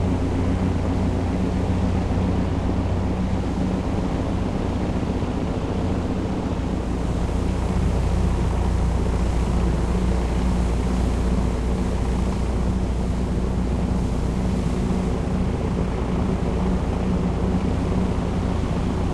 plane_fly_rumble_strong_loop.ogg